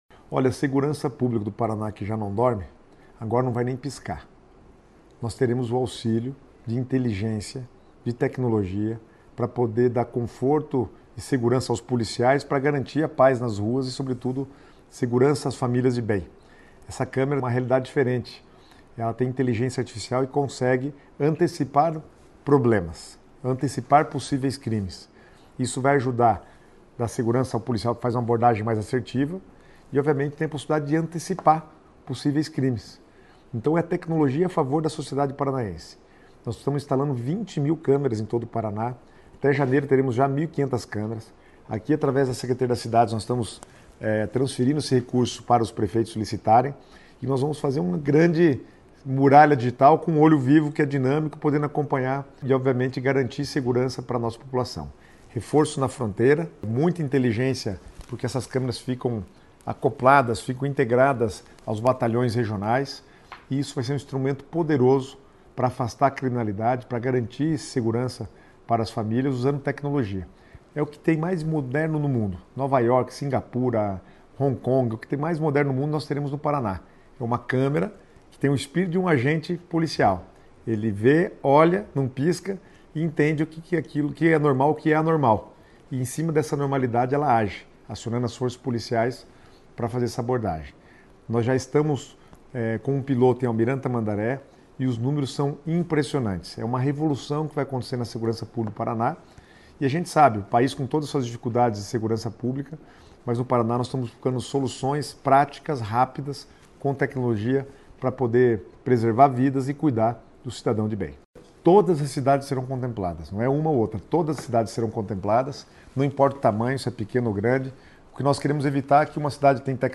Sonora do secretário das Cidades, Guto Silva, sobre o projeto Olho Vivo